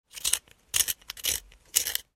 Звуки точилки
Шуршание карандаша в офисной точилке